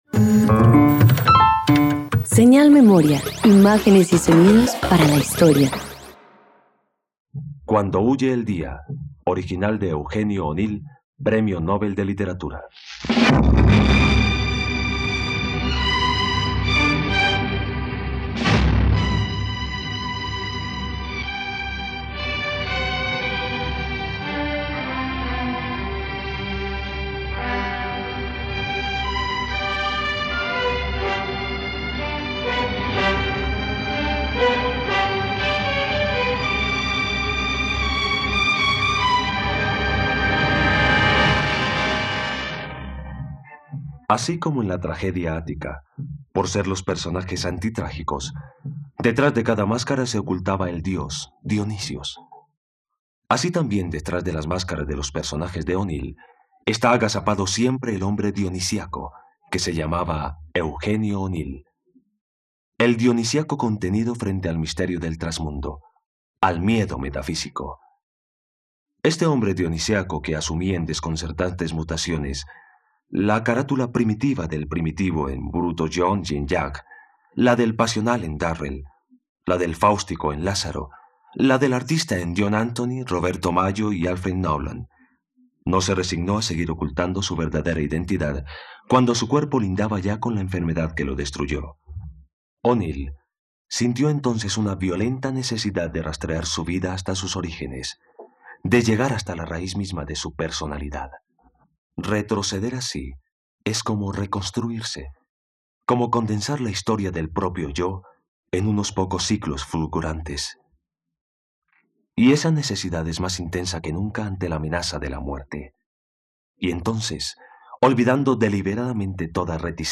Cuando huye el día - Radioteatro dominical | RTVCPlay